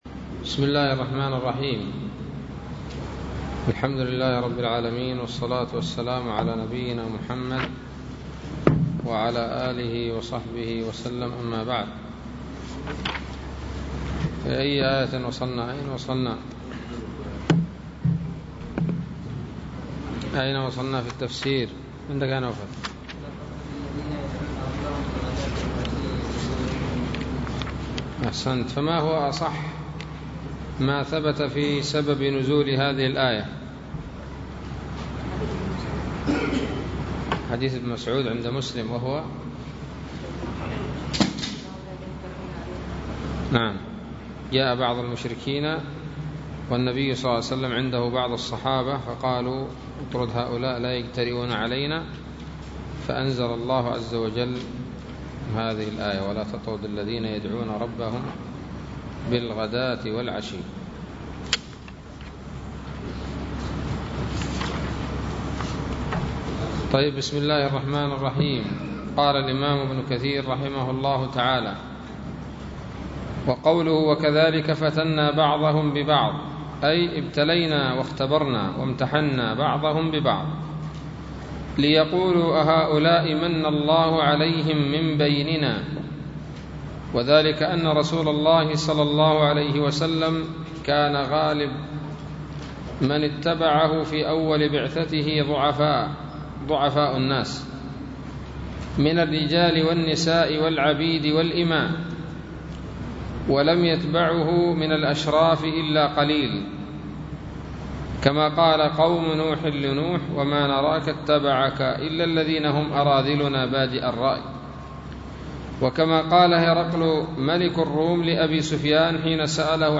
الدرس الثاني عشر من سورة الأنعام من تفسير ابن كثير رحمه الله تعالى